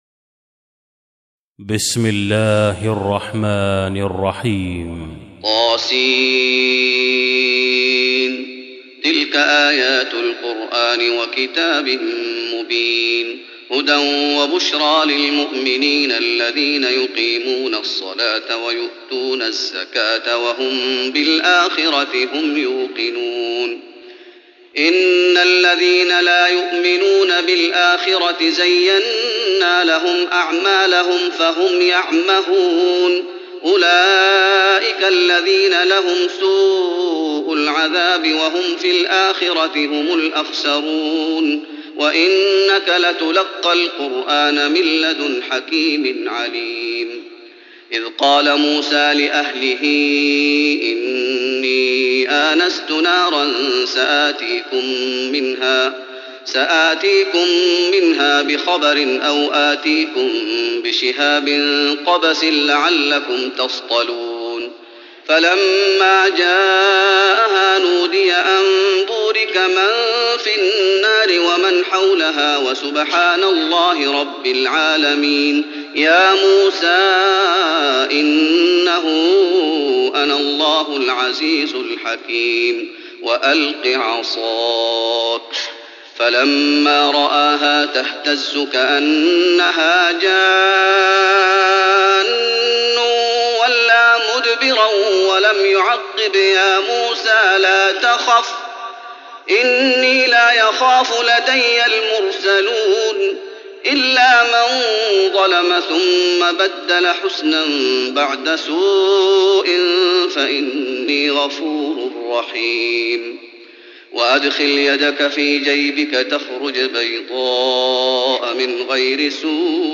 تراويح رمضان 1415هـ من سورة النمل (1-53) Taraweeh Ramadan 1415H from Surah An-Naml > تراويح الشيخ محمد أيوب بالنبوي 1415 🕌 > التراويح - تلاوات الحرمين